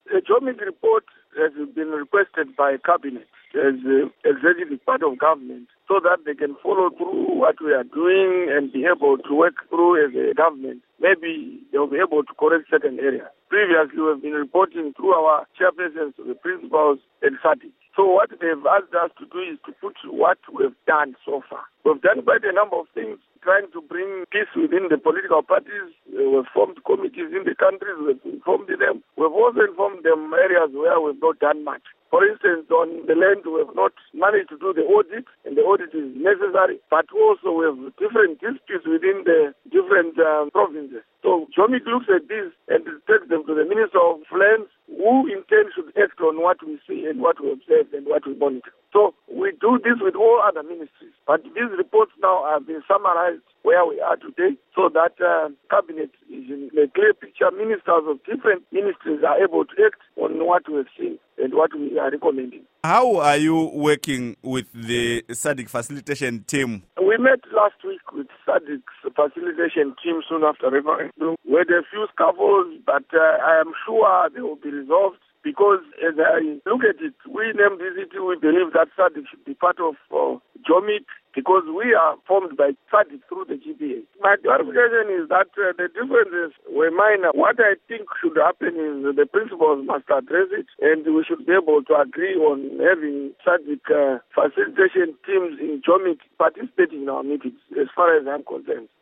Interview With Elias Mudzuri